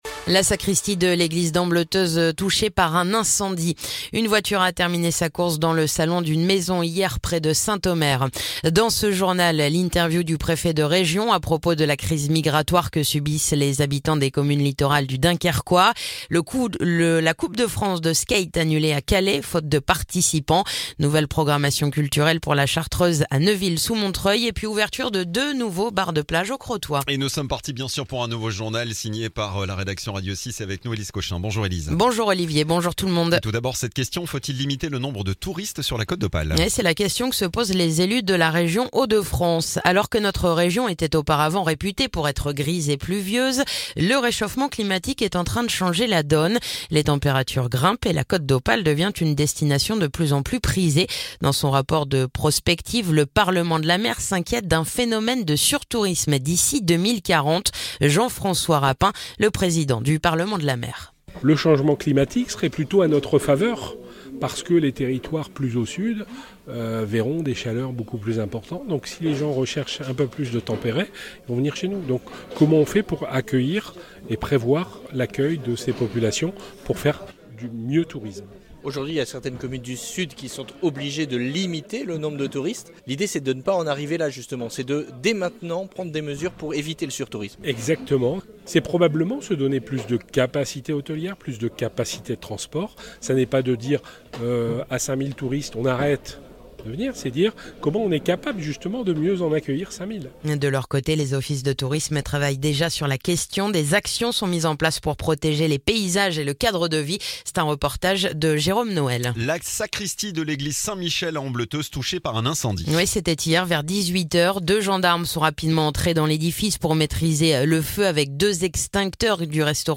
Le journal du vendredi 9 mai